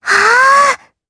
Lilia-Vox_Casting3_jp.wav